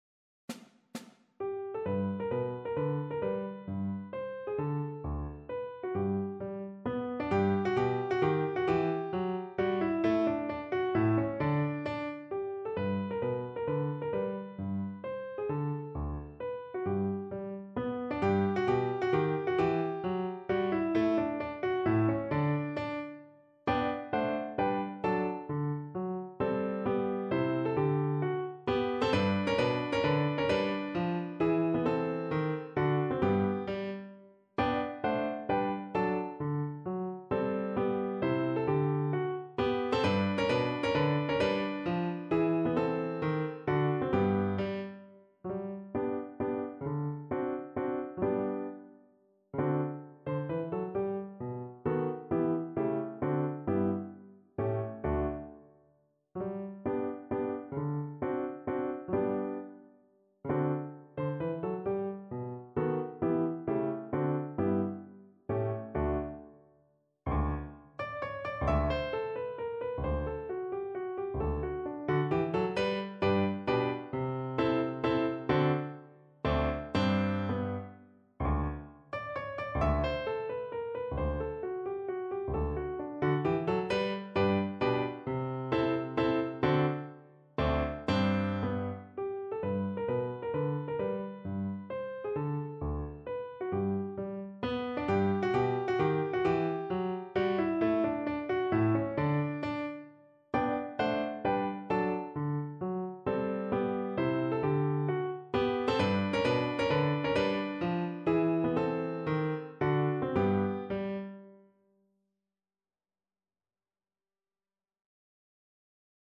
Beethoven: Menuet G-dur (na skrzypce i fortepian)
Symulacja akompaniamentu